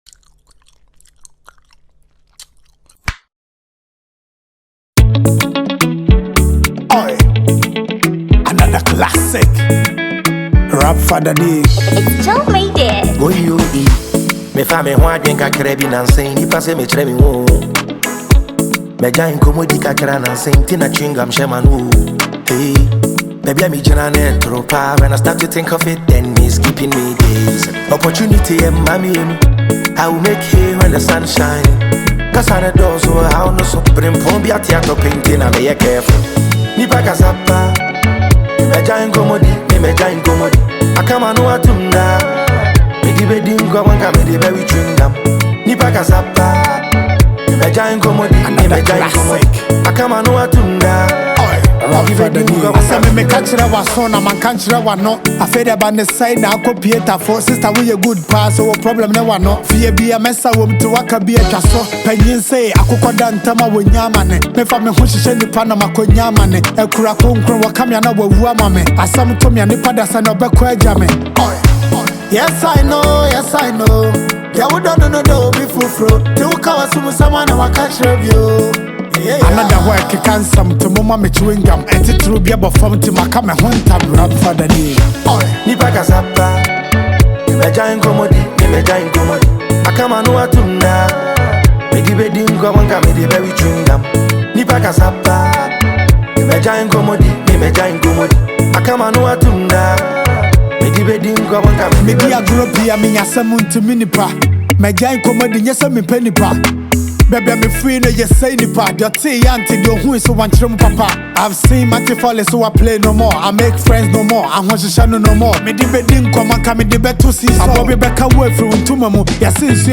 bold, introspective Afro-Hiplife anthem
With rhythmic verses and catchy hooks,